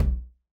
INT Tom.wav